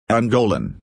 (æŋˈgoʊlən)   angolano (-a)